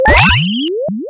8bit Video Game Sounds » Launching 1
描述：Sounds like when one lets go of a balloon that they're pinching shut and it flies around as it's deflating, or that sound could resemble some sort or rocketlauncher being fired.
标签： retro Firearm 8bit sfxr sfx 8bit Video Game Gun Spring SFX arcade Shooting VideoGame Shoot
声道立体声